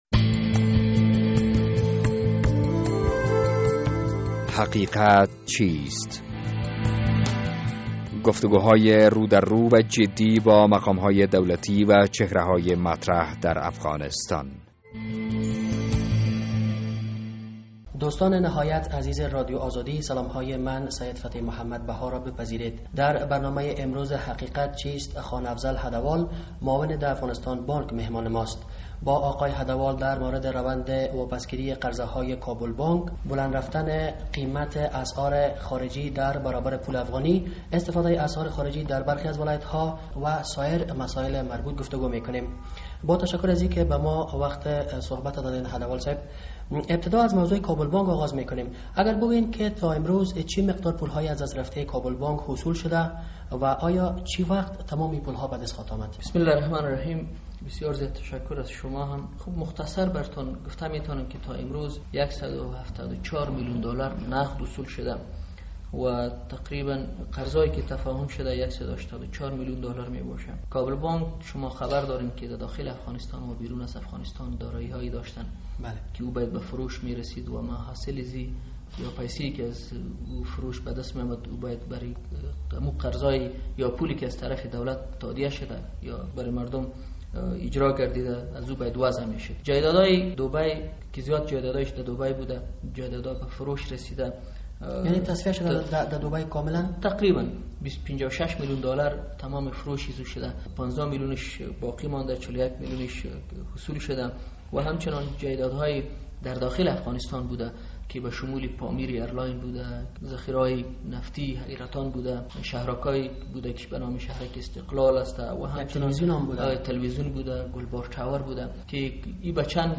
در این برنامهء حقیقت چیست با خان افضل هده وال معاون دافغانستان بانک گفتگو کرده ایم.